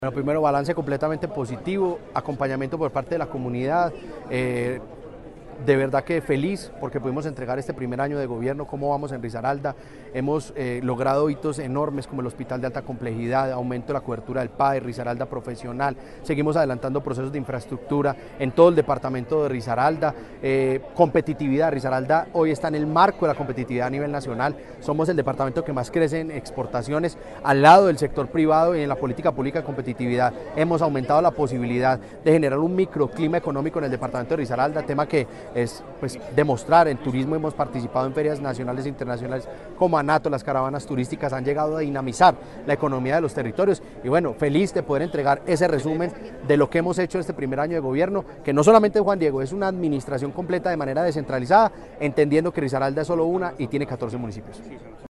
Con un salón a reventar, el gobernador de Risaralda, Juan Diego Patiño Ochoa, presentó su informe de rendición de cuentas al primer año de su mandato.
JUAN-DIEGO-PATINO-OCHOA-GOBERNADOR-DE-RISARALDA.mp3